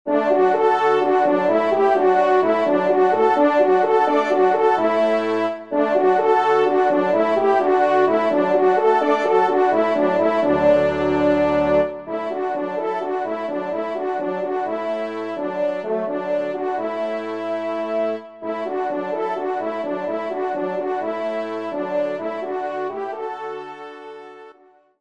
Arrangement Trompe et Piano
ENSEMBLE